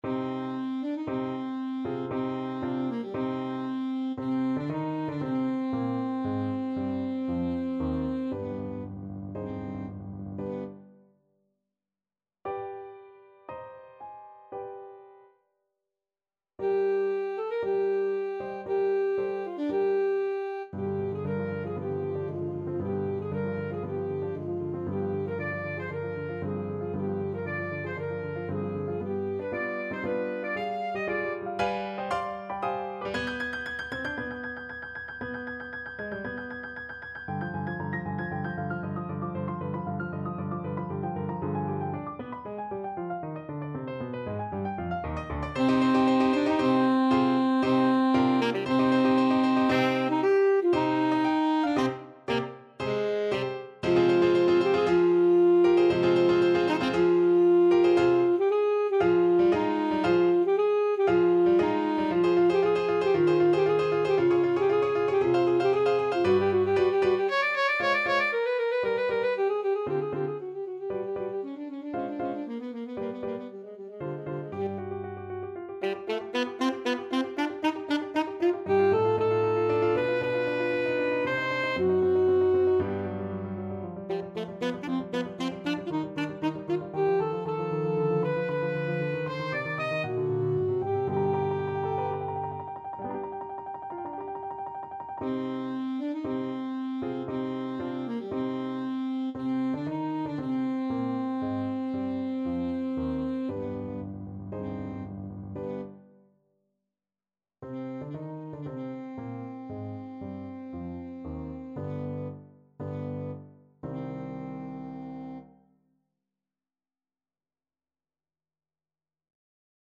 Classical Dvořák, Antonín Concerto for Cello Op.104, 1st Movement Main Theme Alto Saxophone version
C minor (Sounding Pitch) A minor (Alto Saxophone in Eb) (View more C minor Music for Saxophone )
Allegro =116 (View more music marked Allegro)
4/4 (View more 4/4 Music)
Classical (View more Classical Saxophone Music)
dvorak_cello_concerto_1st_main_ASAX.mp3